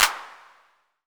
808-Clap03.wav